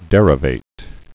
(dĕrə-vāt)